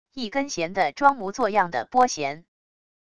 一根弦的装模作样的拨弦wav音频